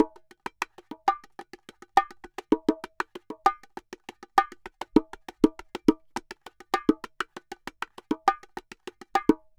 Bongo_Salsa 100_3.wav